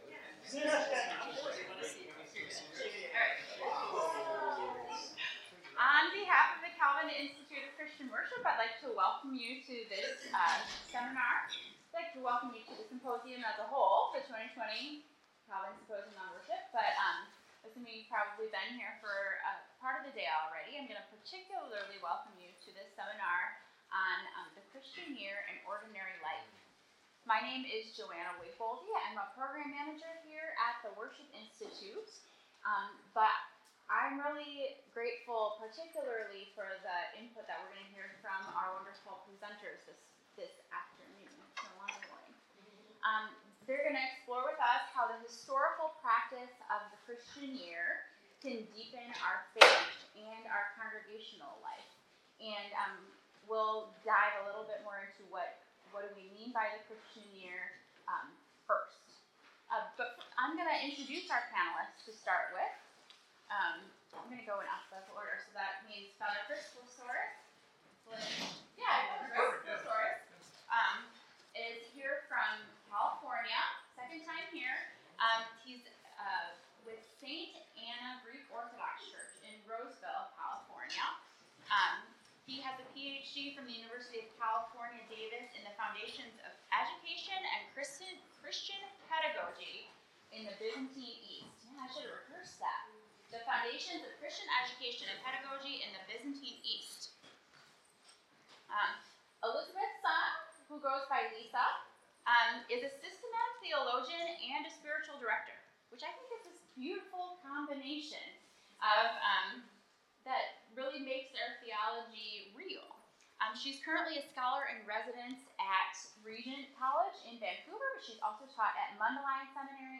Details 2020 Calvin Symposium on Worship | Seminar All calendars provide a rhythm and shape to our lives.